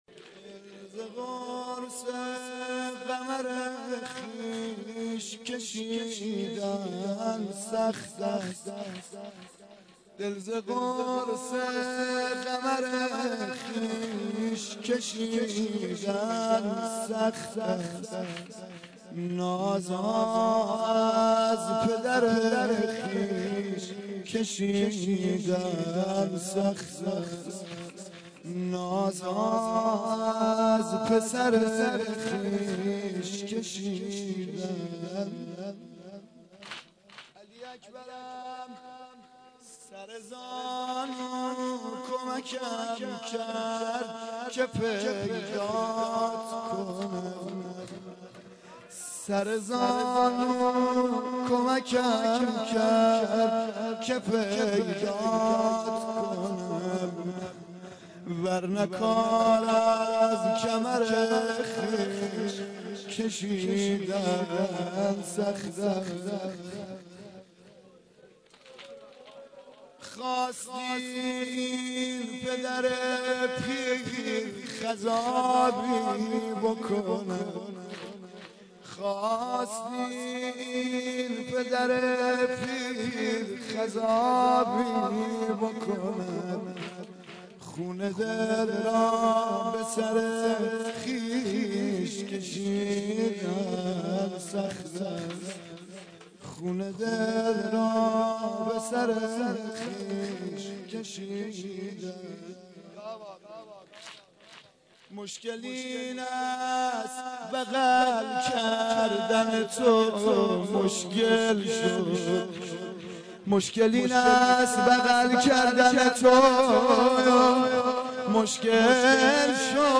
واحد شب هشتم محرم